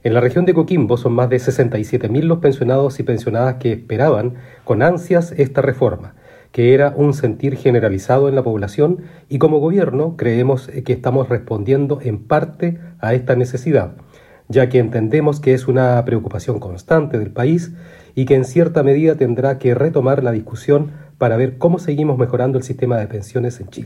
Seremi-del-Trabajo-24032.mp3